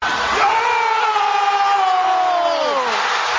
the shot Meme Sound Effect
This sound is perfect for adding humor, surprise, or dramatic timing to your content.